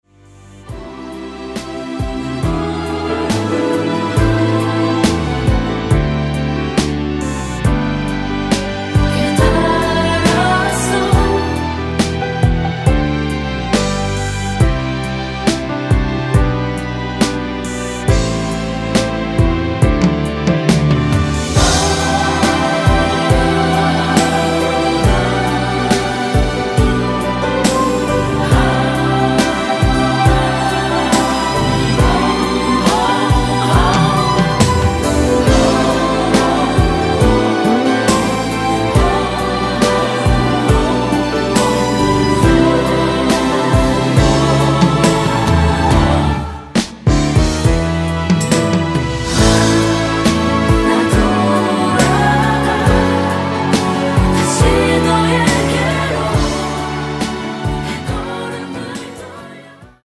코러스 포함된 MR 입니다.(미리듣기 참조 하세요~)
앞부분30초, 뒷부분30초씩 편집해서 올려 드리고 있습니다.
중간에 음이 끈어지고 다시 나오는 이유는